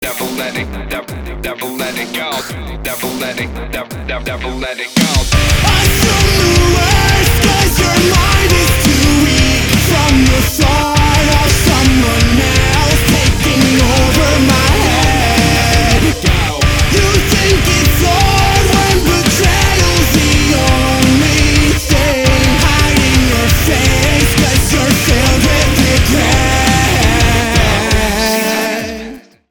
Kategória: Rock
Minőség: 320 kbps 44.1 kHz Stereo